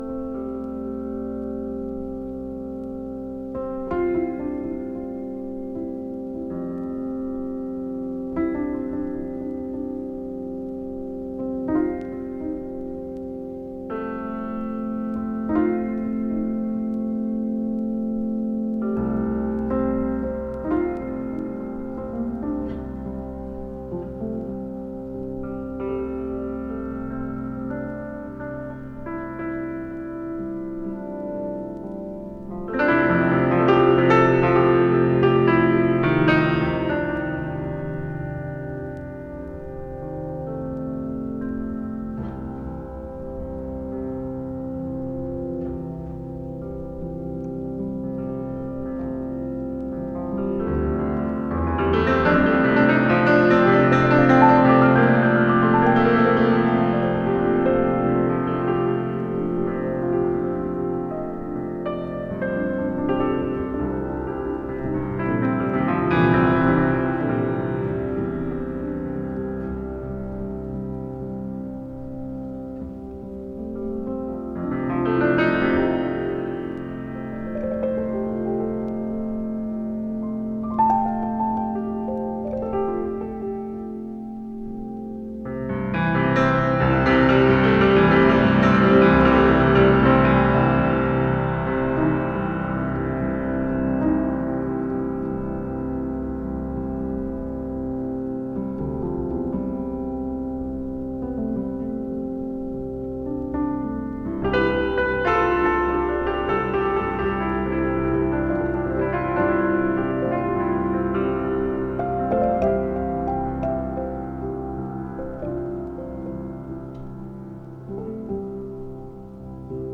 Ambient Jazz